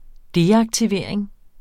Udtale [ ˈdeɑgtiˌveˀɐ̯eŋ ]